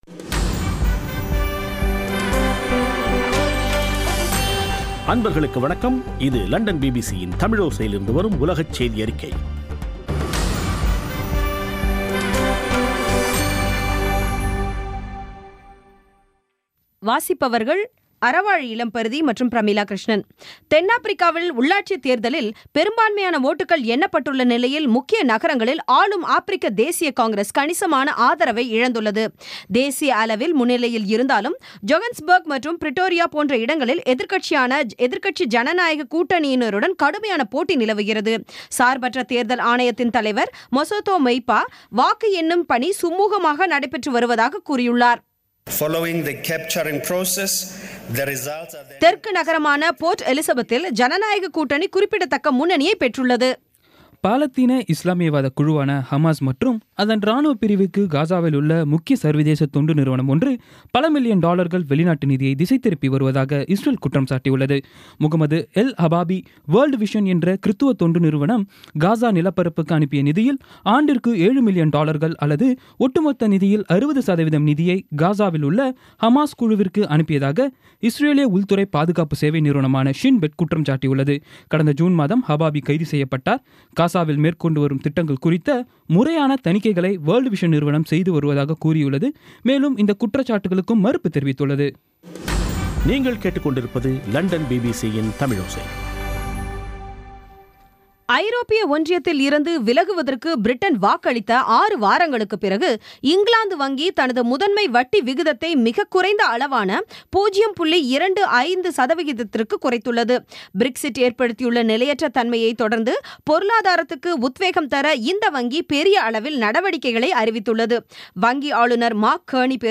இன்றைய (ஆகஸ்ட் 4-ம் தேதி ) பிபிசி தமிழோசை செய்தியறிக்கை